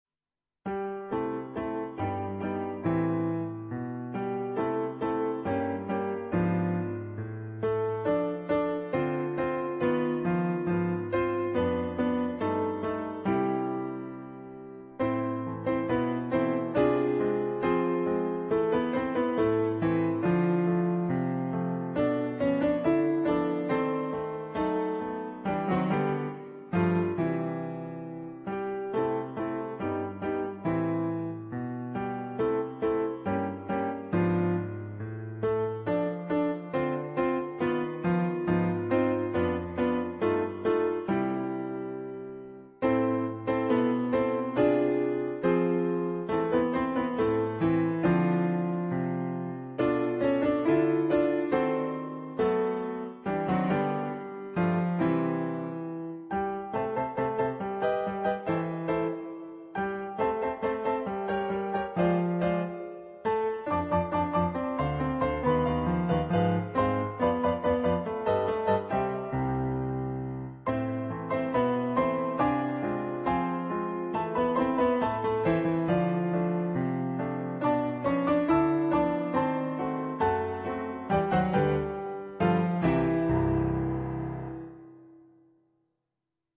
adapted for Two Pianos
on Yamaha digital pianos.